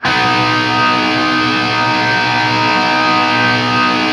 TRIAD B  L-R.wav